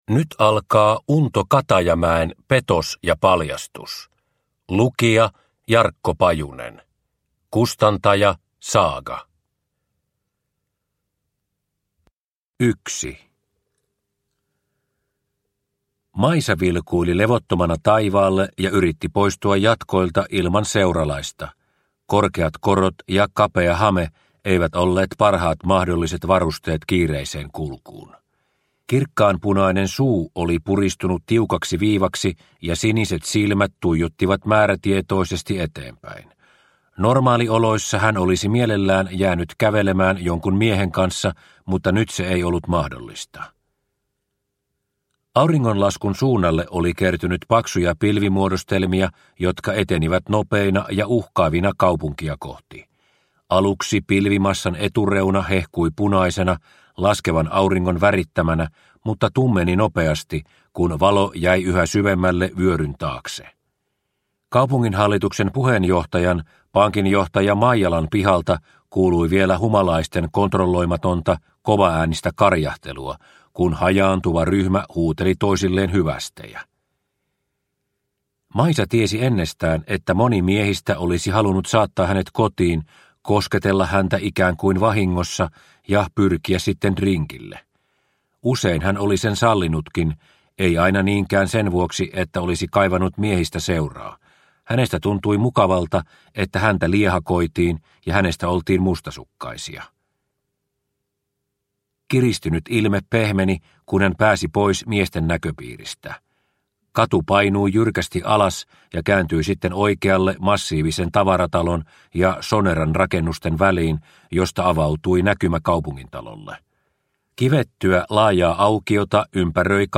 Petos ja paljastus (ljudbok) av Unto Katajamäki | Bokon